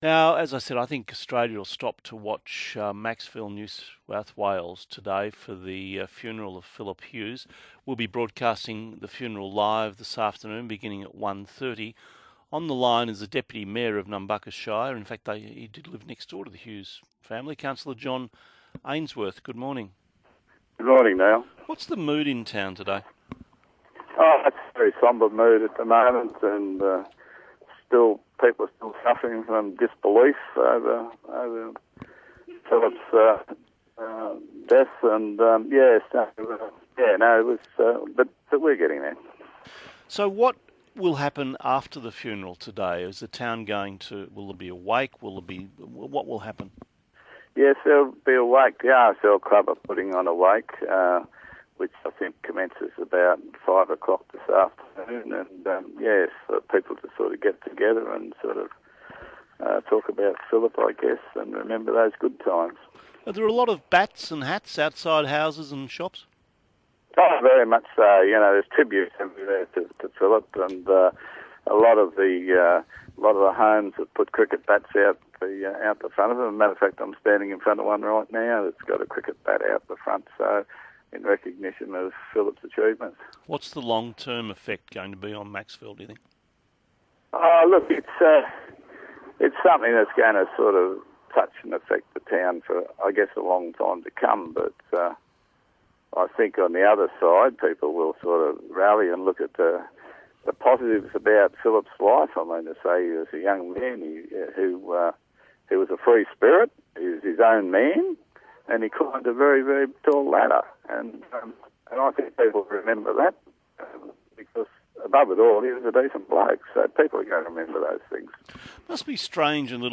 Macksville councillor speaks with Neil Mitchell ahead of funeral for Phillip Hughes.